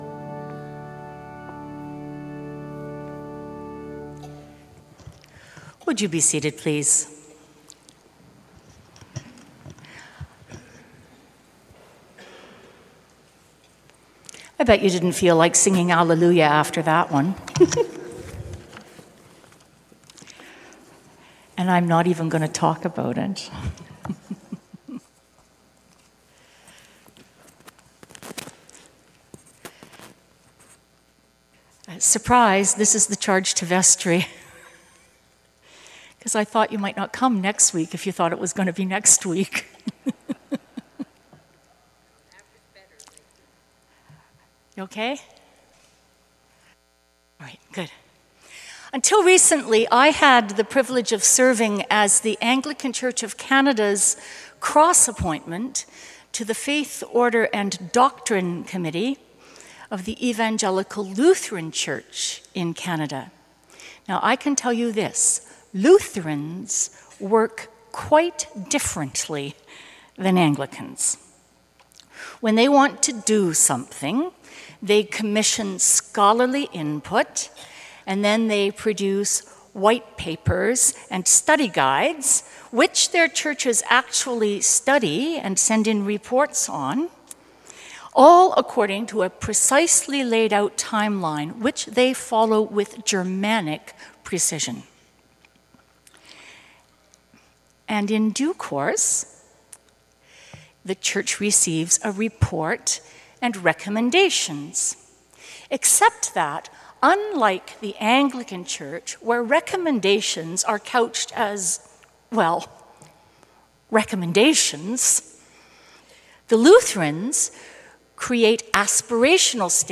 Sermon Audio | Christ Church Cathedral